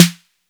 Snare (Take Care).wav